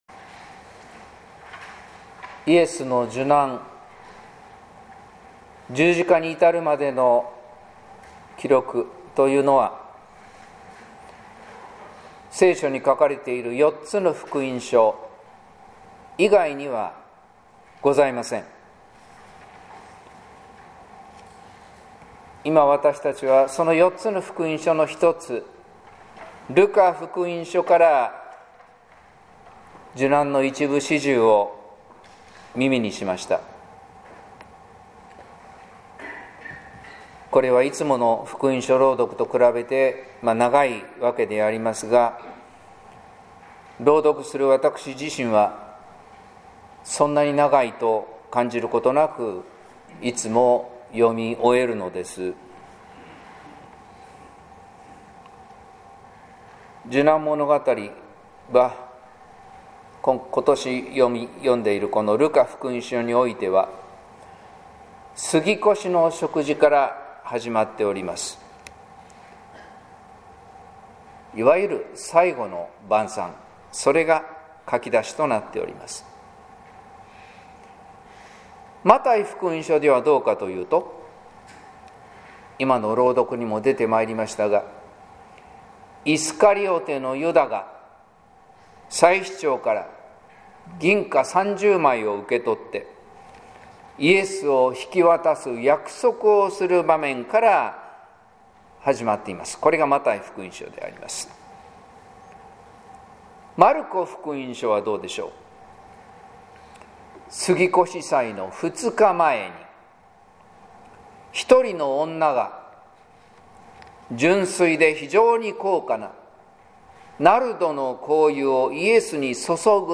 説教「最後の晩餐の言葉」（音声版） | 日本福音ルーテル市ヶ谷教会